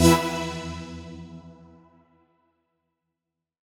FR_ZString[hit]-E.wav